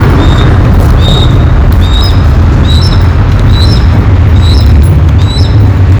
Harris´s Hawk (Parabuteo unicinctus)
Location or protected area: Parque Nacional El Palmar
Condition: Wild
Certainty: Photographed, Recorded vocal